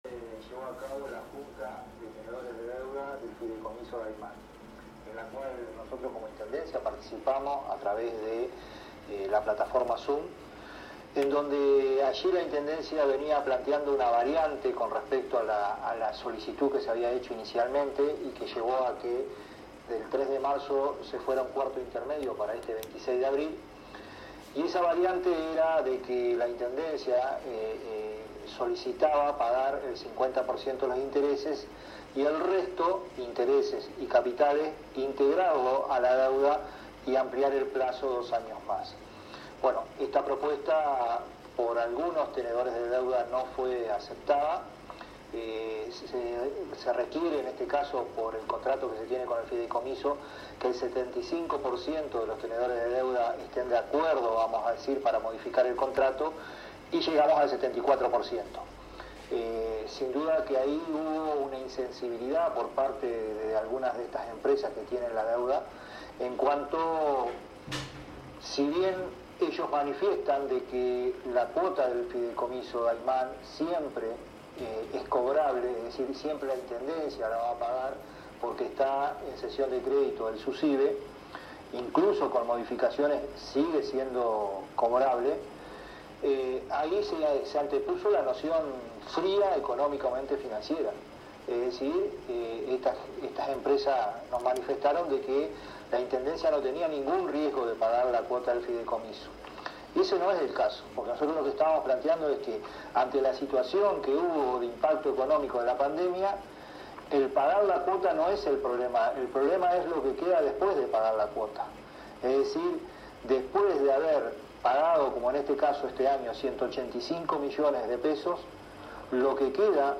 rueda de prensa ... - CIRCUITO 21